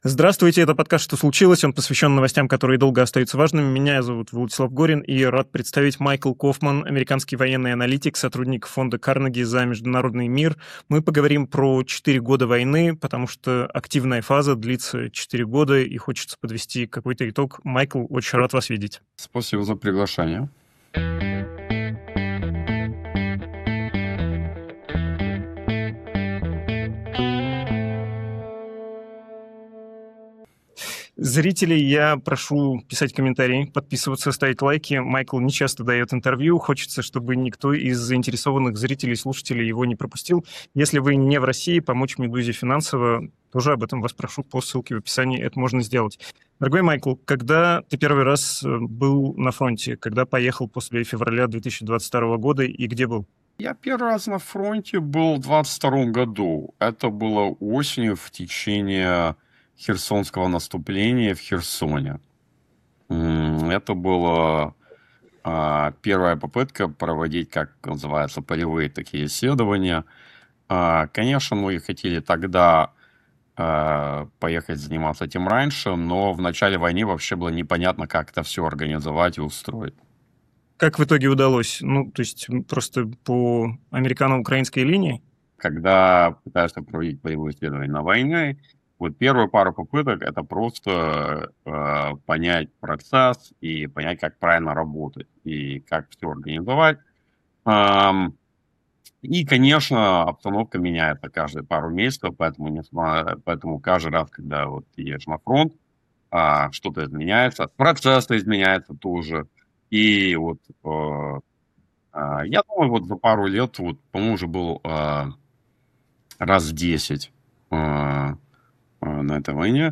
Обсуждаем с Майклом Кофманом, американским военным аналитиком и сотрудником Фонда Карнеги за международный мир.